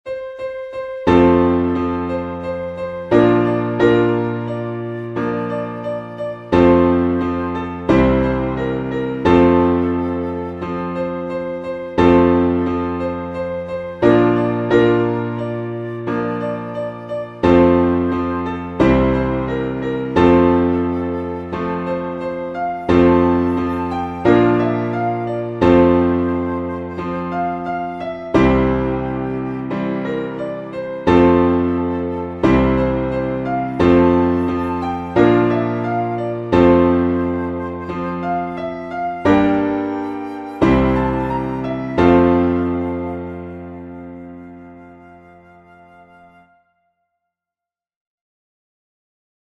• Beginner-friendly range and rhythm
• Easy flute solo with expressive phrasing